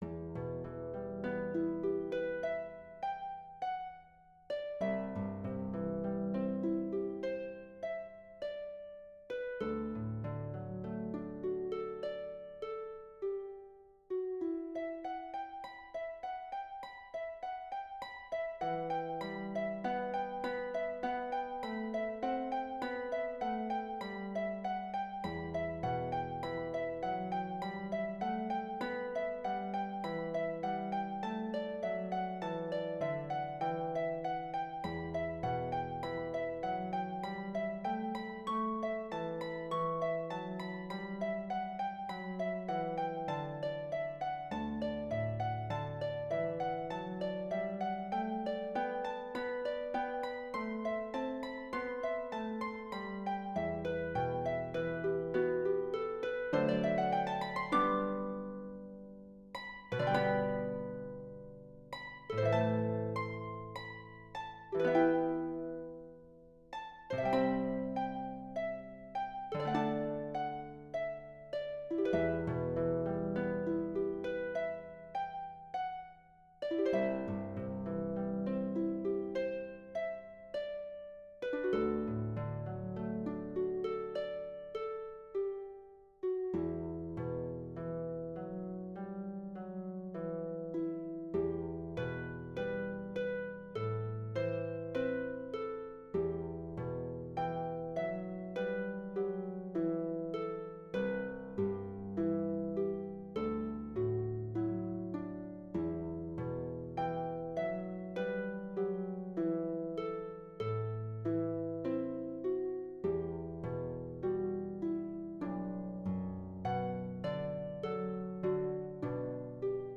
Harp Solo
Christmas hymn arrangement.